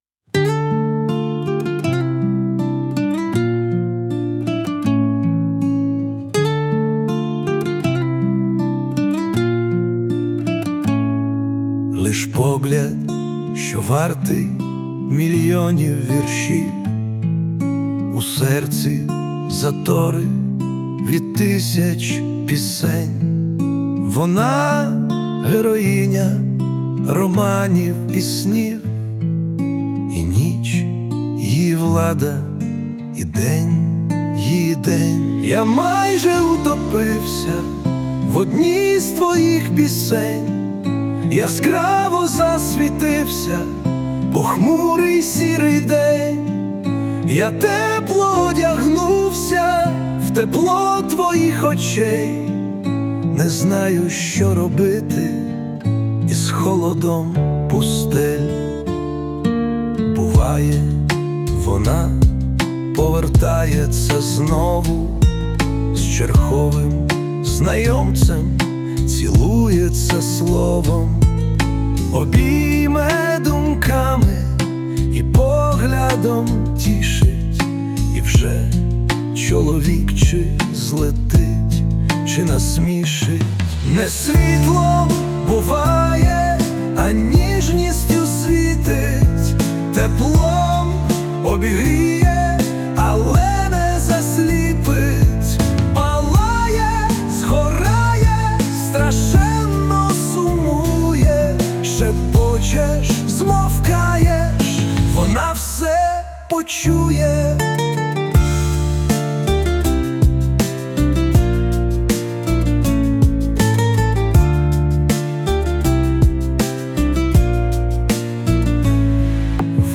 Я майже утопився в одній з твоїх пісень (Cover).mp3
Кавер зроблено з допомогою SUNO